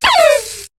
Cri de Lixy dans Pokémon HOME .